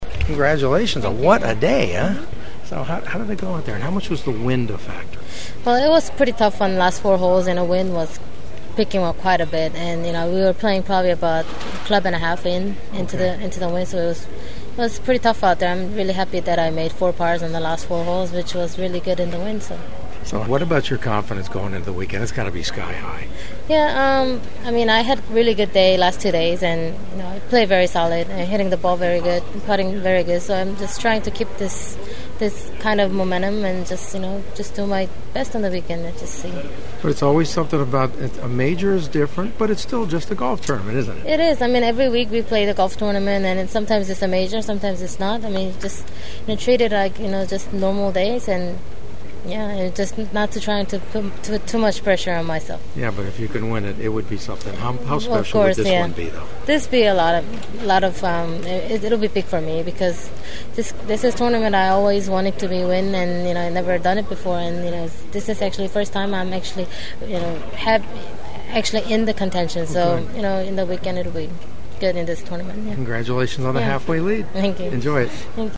And on a whim, I walked into the media tent just in time to see the two 2nd round leaders finishing up their newsconferences.